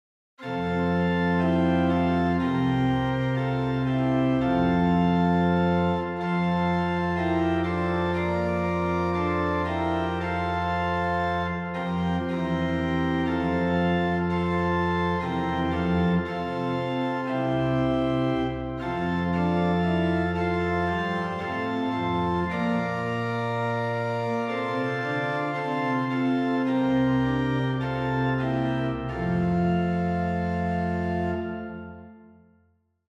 Posnetki z(+) in brez uvoda
in so obrezani za ponavljanje / loop / kitice
samo prvi del